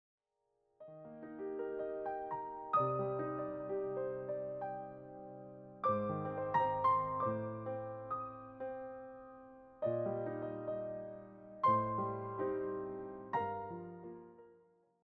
all rendered as solo piano pieces.
intimate, late-night atmosphere